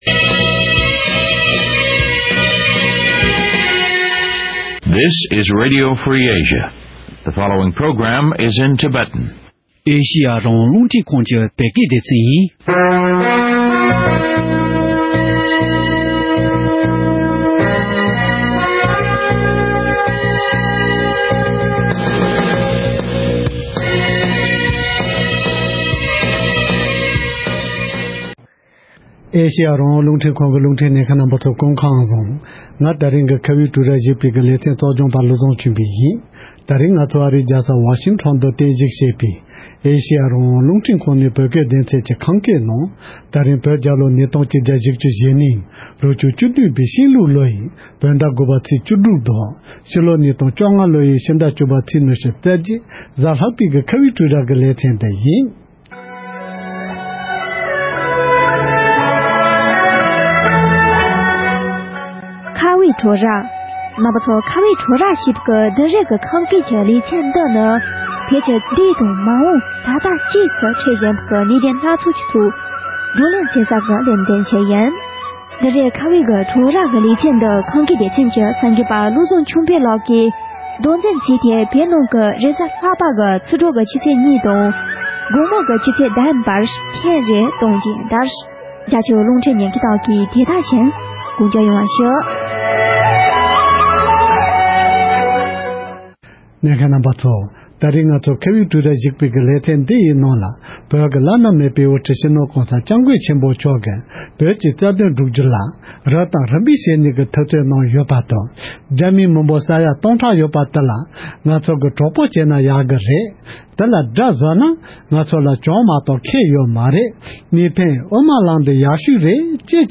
༄༅། །ཐེངས་འདིའི་ཁ་བའི་གྲོས་རྭ་ཞེས་པའི་ལེ་ཚན་ནང་བོད་ཀྱི་བླ་ན་མེད་པའི་དབུ་ཁྲིད་སྤྱི་ནོར་༧གོང་ས་༧སྐྱབས་མགོན་ཆེན་པོ་མཆོག་གིས་བོད་རྩ་དོན་གྲུབ་རྒྱུ་ལ་རབ་དང་རིམ་པ་བྱས་ནས་འཐབ་རྩོད་གནང་ཡོད་པ་དང་། རྒྱ་མིའི་མི་འབོར་ས་ཡ་སྟོང་ཕྲག་གཅིག་ལྷག་ཡོད་པ་དེ་ང་ཚོ་གྲོགས་པོ་བྱེད་ན་ཡག་གི་རེད། དེ་ལ་དགྲ་བཟོས་ན་བོད་པ་ལ་གྱོང་མ་གཏོགས་ཁེ་ཡོད་མ་རེད། དེར་བརྟེན་གཉིས་ཕན་དབུ་མའི་ལམ་དེ་ཡག་ཤོས་རེད་བཅས་གལ་ཆེ་བའི་བཀའ་སློབ་གནང་བ་ཁག་ཅིག་ཕྱོགས་སྒྲིག་ཞུས་པར་གསན་རོགས་གནང་།